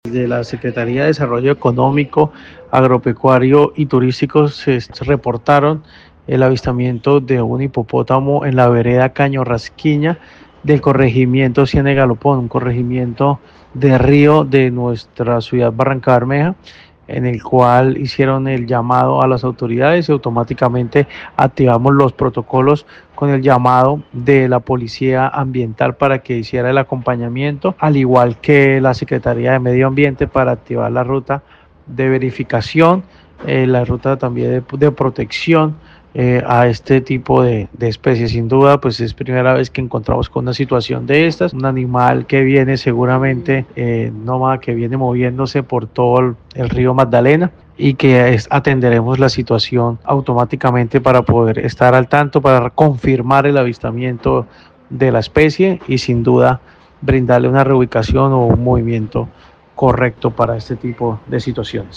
Secretario de Ambiente y Transición Energética de Barrancabermeja, Leonardo Granados Cárdenas